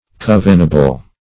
Search Result for " covenable" : The Collaborative International Dictionary of English v.0.48: Covenable \Cov"e*na*ble\ (k?v"?-n?-b'l), a. [OF. covenable, F. convenable.